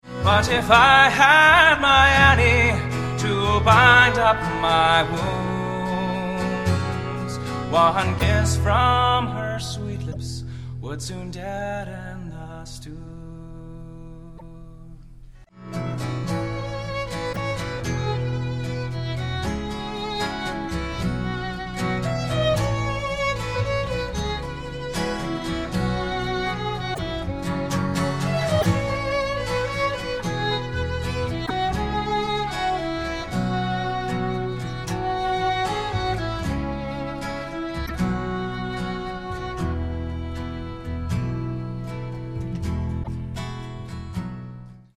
instrumental and vocal folk music of Ireland